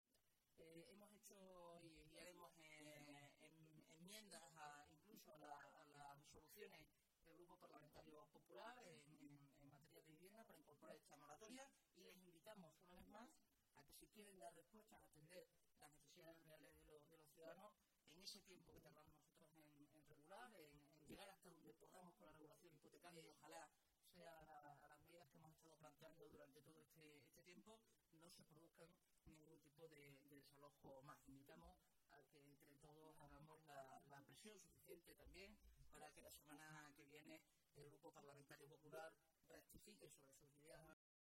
Momento de la rueda de prensa anterior a las jornadas